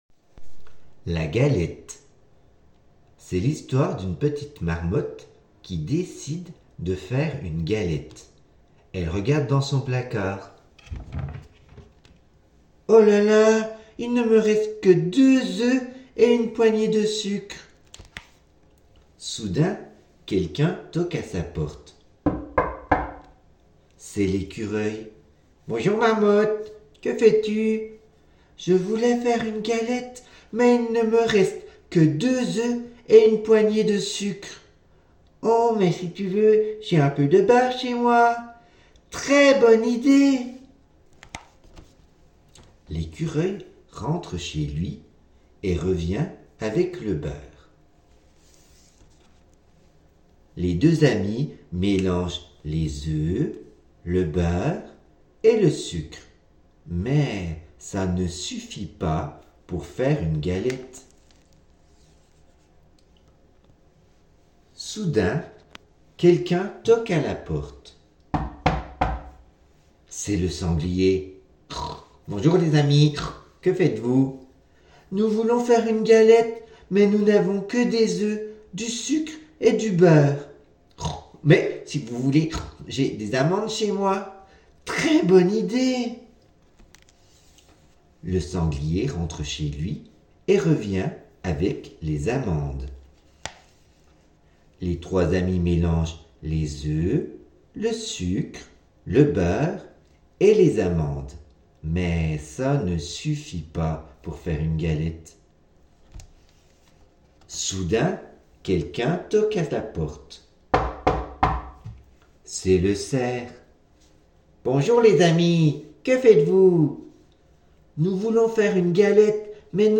3 – Entendre l’histoire plusieurs fois…en lecture offerte, en mode théâtre, en écoute enregistrée…